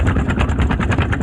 WheelFlat.wav